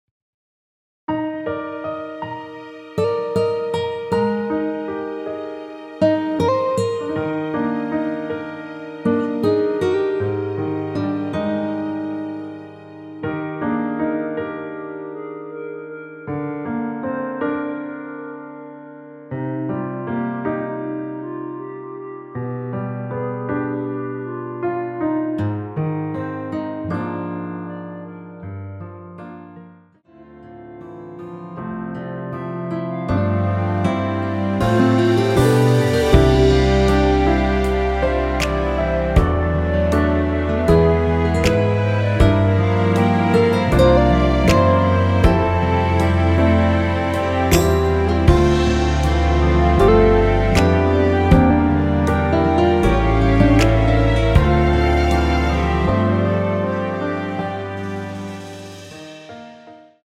원키에서(-1)내린 멜로디 포함된 MR입니다.(미리듣기 확인)
Eb
앞부분30초, 뒷부분30초씩 편집해서 올려 드리고 있습니다.
중간에 음이 끈어지고 다시 나오는 이유는